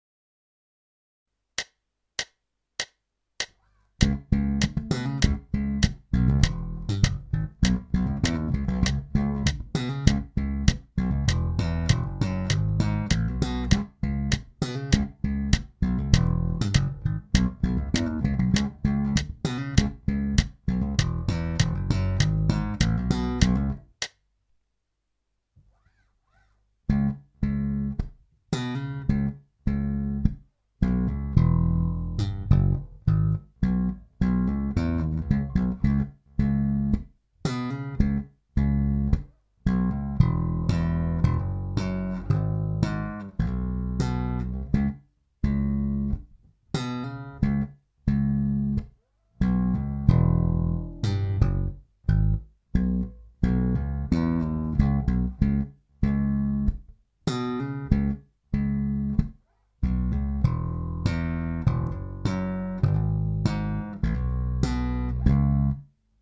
L46 Slap Bass line in Cm
L46-Slap-line-in-C-m.mp3